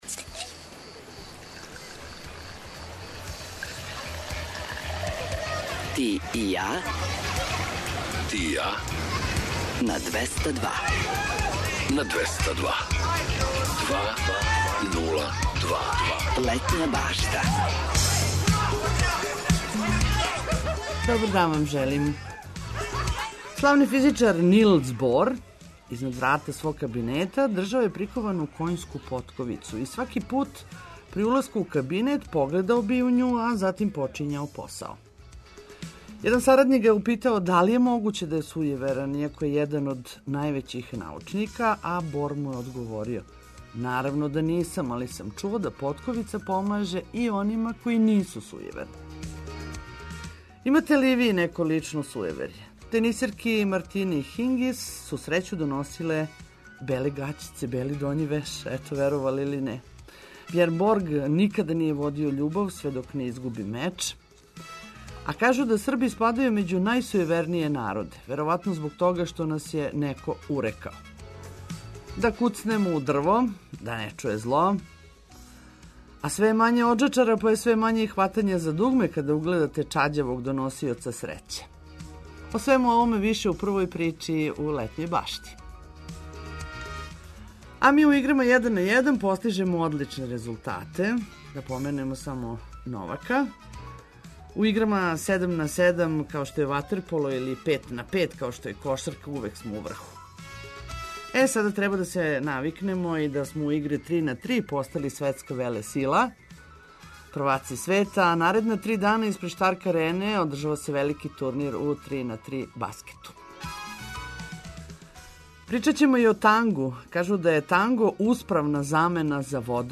Репортери ће вам пренети део атмосфере испред Арене где почиње 3-на-3 турнир у баскету и оног што очекује Новосађане на међународном фестивалу танга.